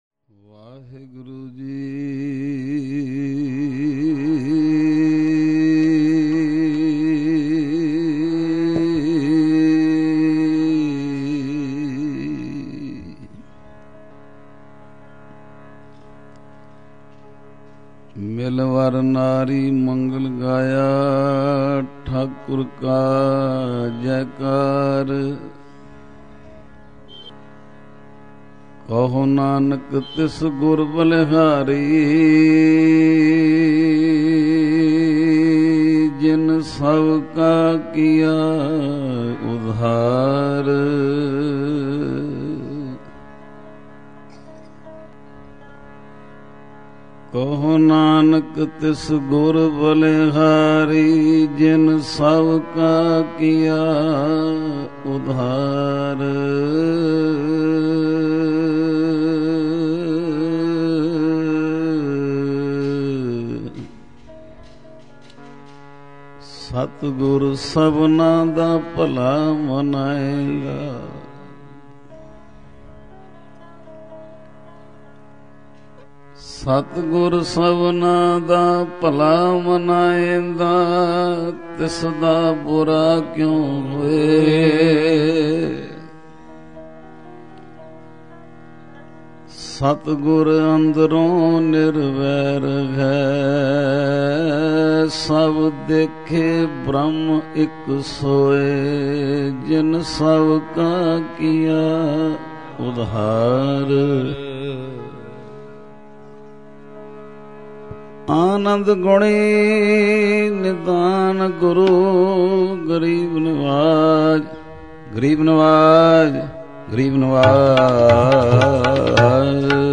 shabad Kirtan with katha